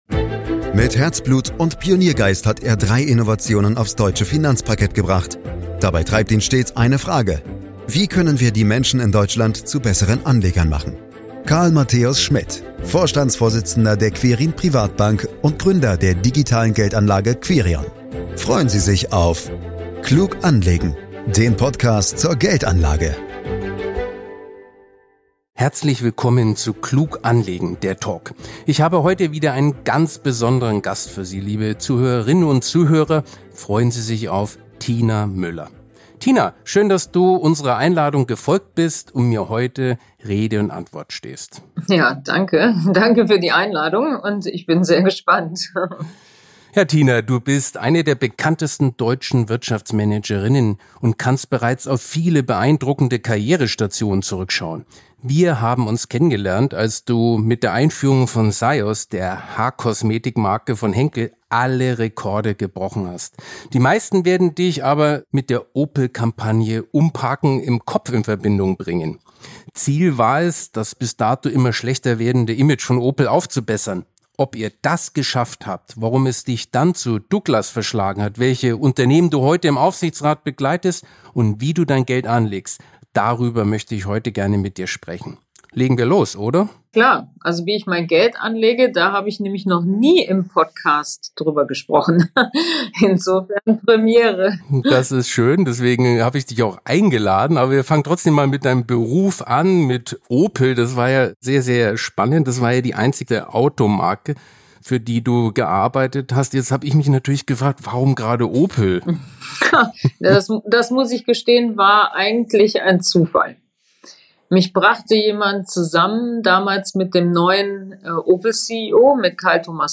Ob sie das geschafft hat, warum es sie dann als CEO zu Douglas verschlagen hat und wie sie ihr Geld anlegt, das ist Thema dieser Podcast-Folge. Dabei freuen wir uns ganz besonders, dass Tina Müller erstmals Fragen zu ihrer Geldanlage in einem Podcast beantwortet, u. a. woher die Affinität für Finanzen kommt, welche Investments und Immobilien sie hat, was man für Werte in ihrem Depot findet und welche Erfahrungen sie mit Private Equity gemacht hat – eine kleine Premiere sozusagen.